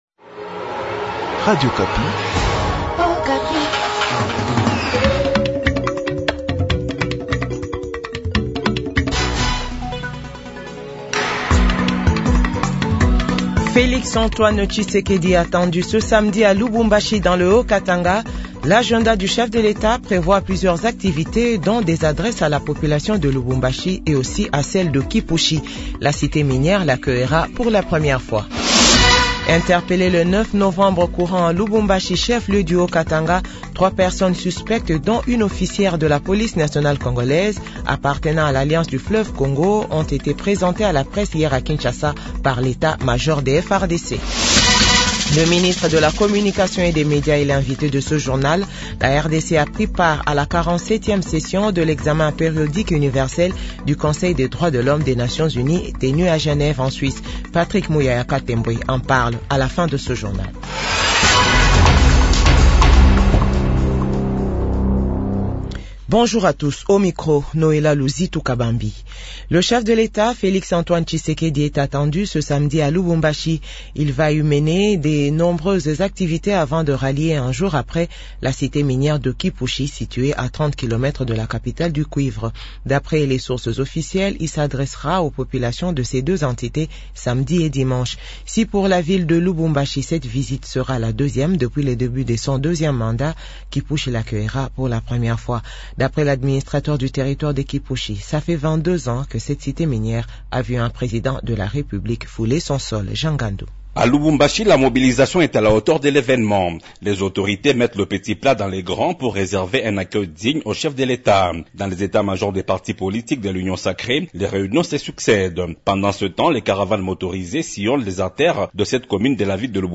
JOURNAL FRANCAIS 7H/8H